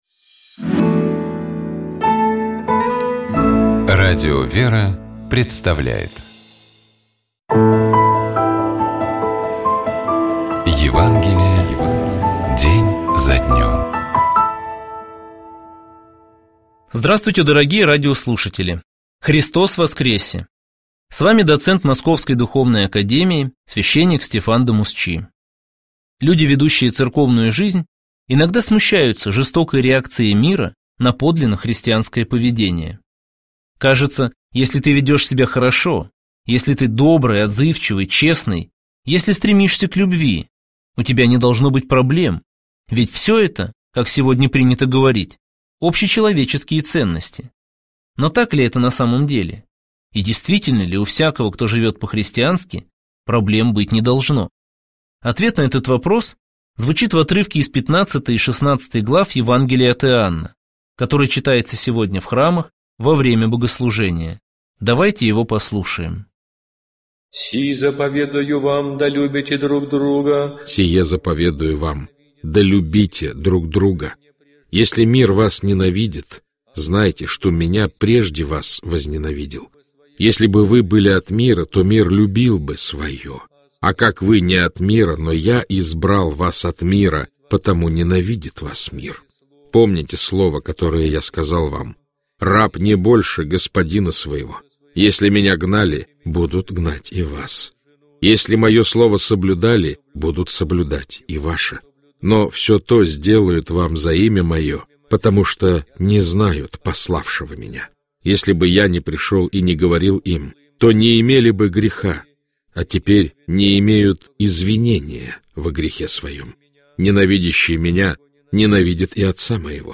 Читает и комментирует епископ Переславский и Угличский Феоктист